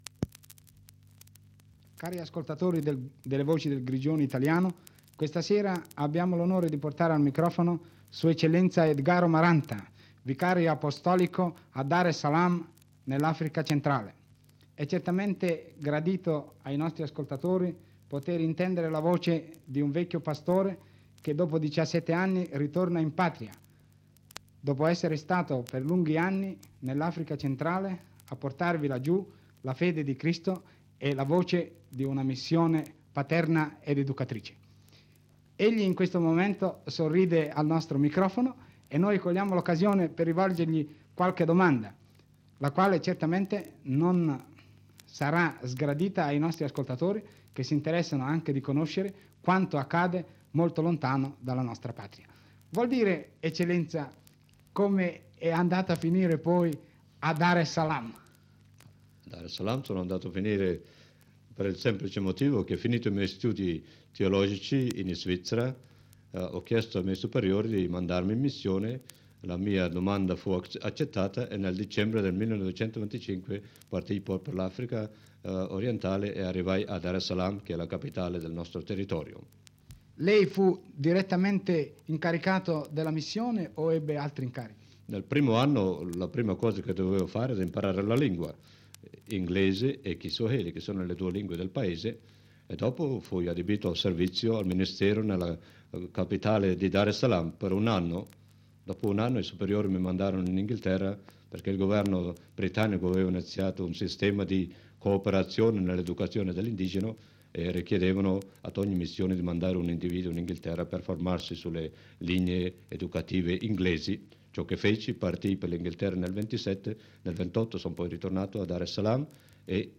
L’intervista si conclude con un saluto in swahili, rivolto a tutti i suoi compatrioti poschiavini.